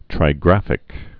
(trī-grăfĭk)